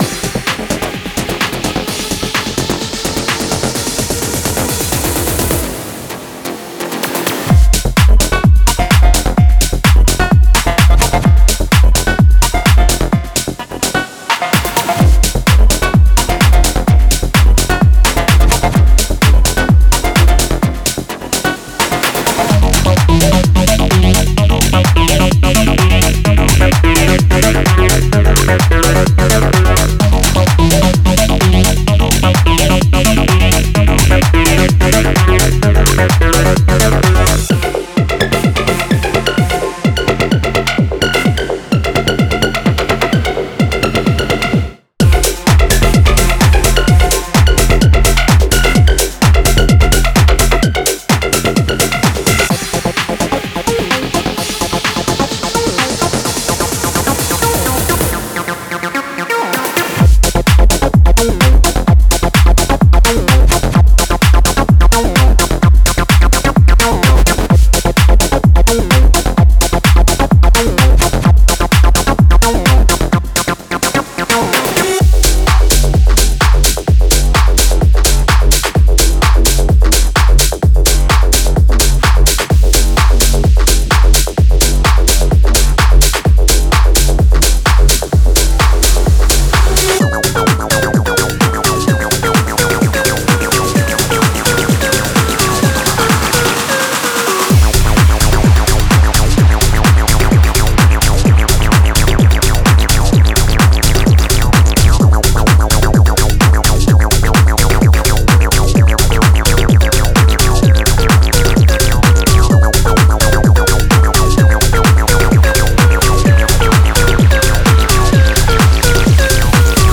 House Tech House Techno